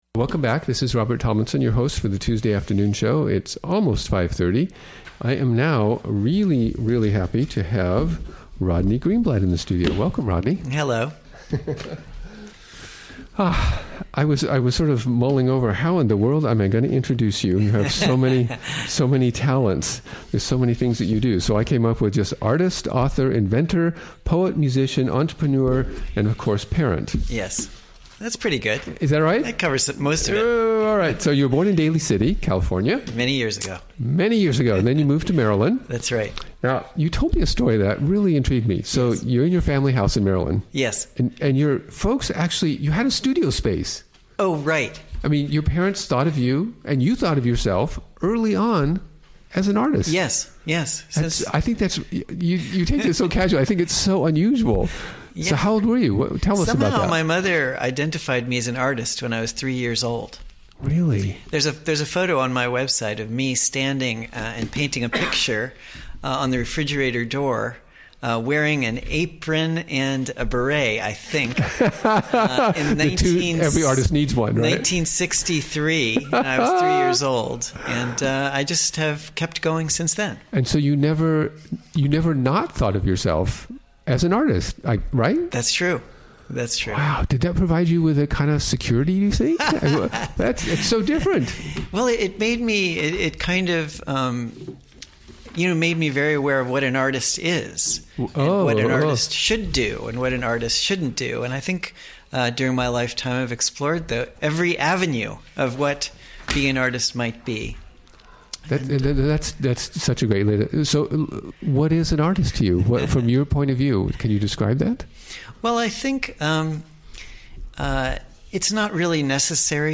Interview
Recorded during the WGXC Afternoon Show of Tuesday, March 28, 2017.